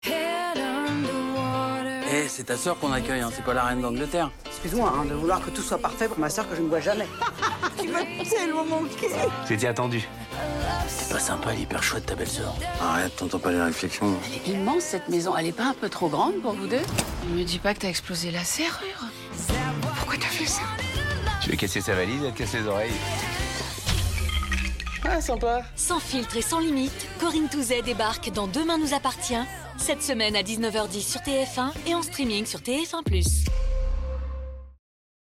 BANDE ANNONCE
Voix off